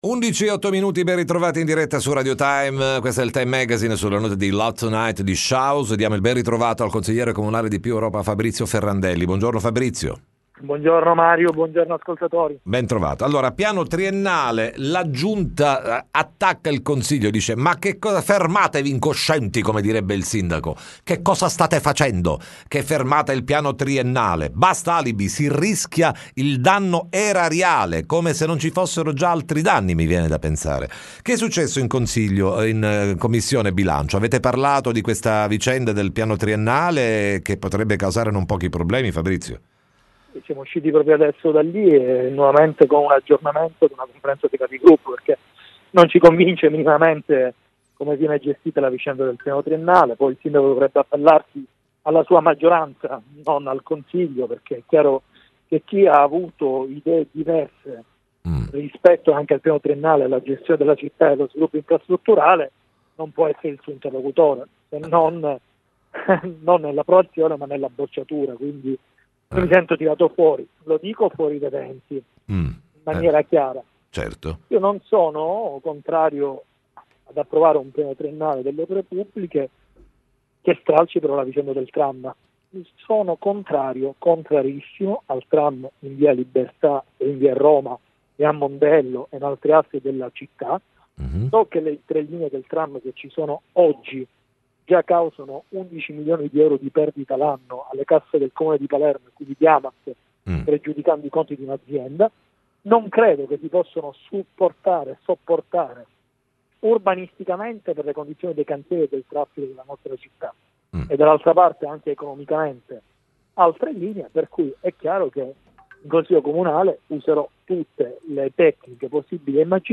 TM Intervista Fabrizio Ferrandelli